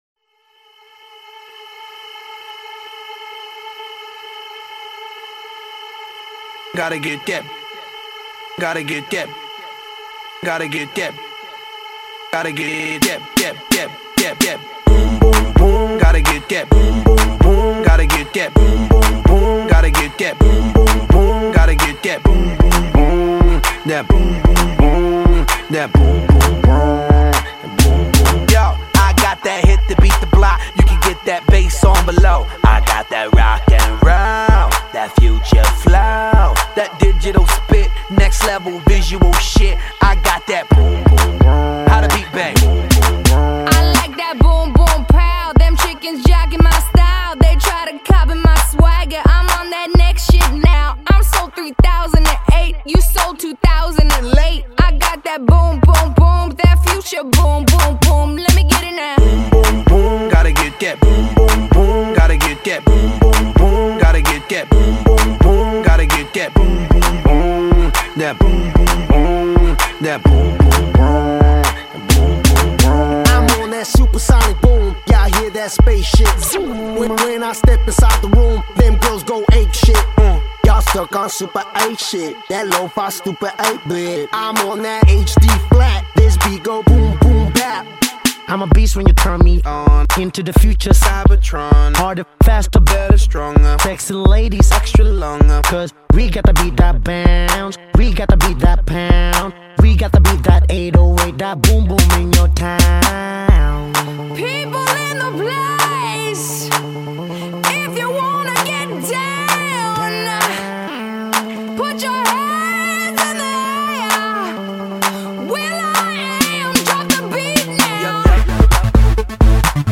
მთავარი » ფაილები » MuSiKeBi » ElEkTrOnUlI